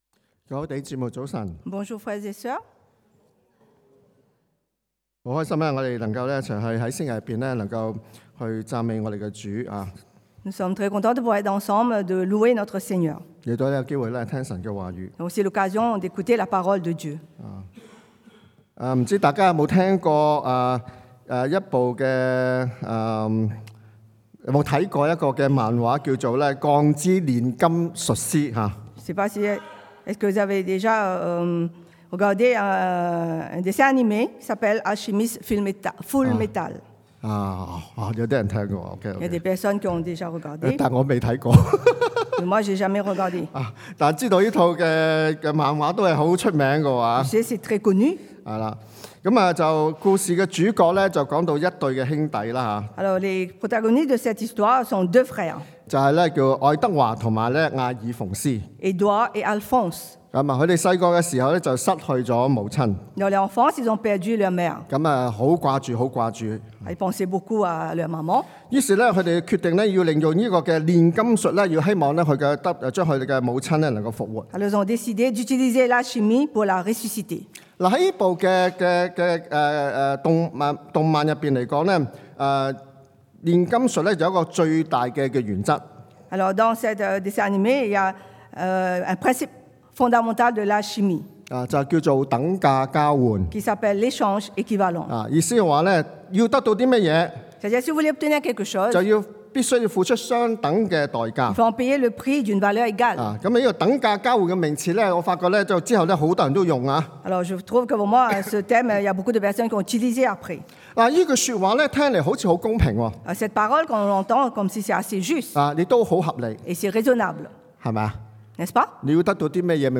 L’échange de vie 生命的交換 – Culte du dimanche